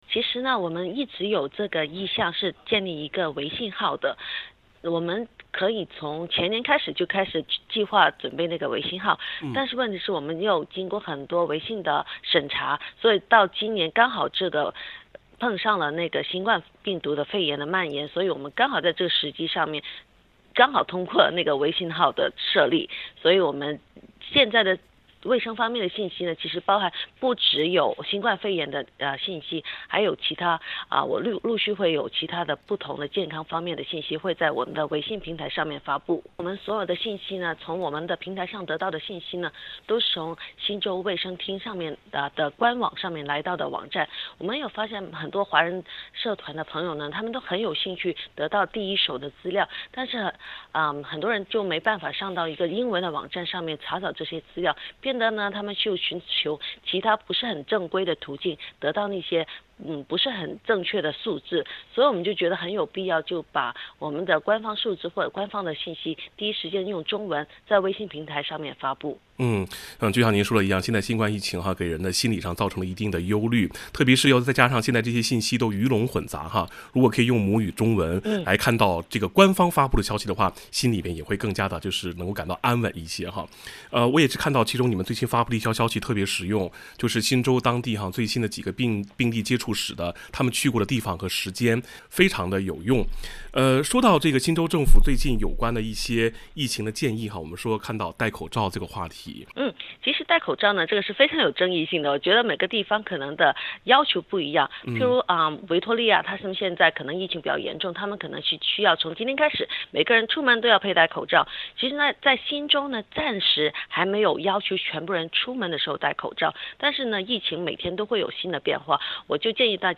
新南威尔士州卫生部（NSW Health）通过下属的新州多元文化健康信息服务(NSW Multicultural Health Communication Service) 开通了微信公众号，用中文向当地华人社区更新政府发布的疫情公共卫生和健康信息，让有英语语言障碍的人士也能用母语掌握第一手疫情咨询。（点击封面图片，收听完整采访）